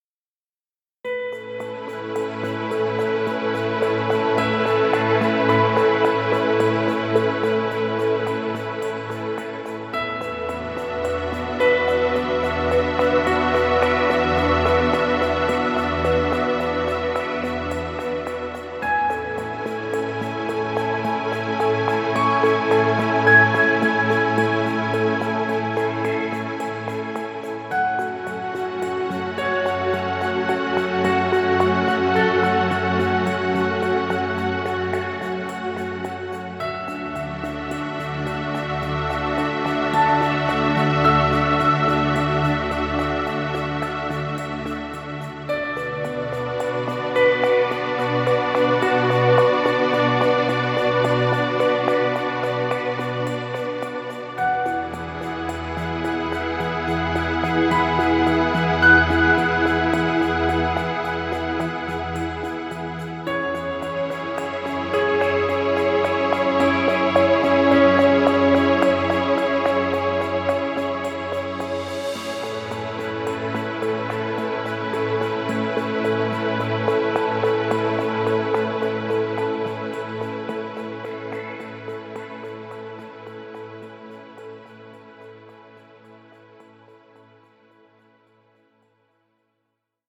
Royalty Free Music.